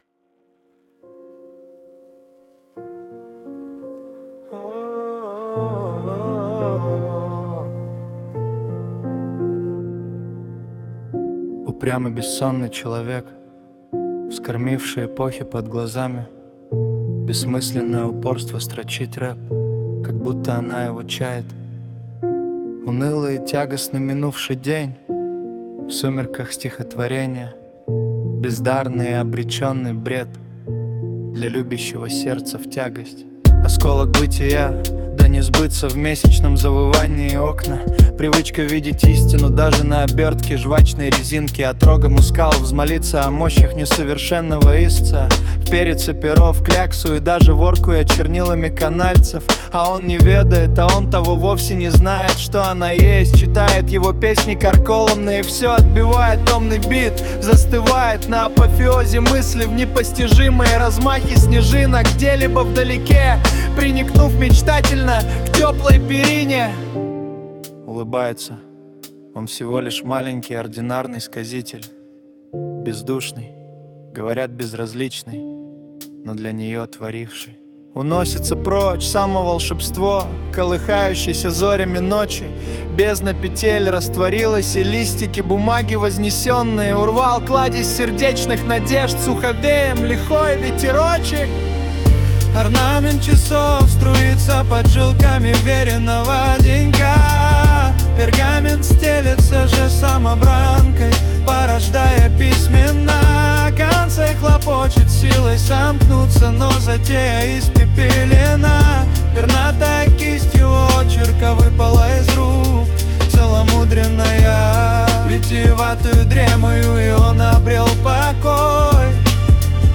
Музыка и исполнение принадлежит ИИ.
ТИП: Пісня
СТИЛЬОВІ ЖАНРИ: Романтичний